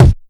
Kick_96.wav